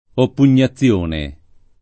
oppugnazione [ oppun’n’a ZZL1 ne ] s. f.